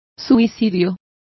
Complete with pronunciation of the translation of suicide.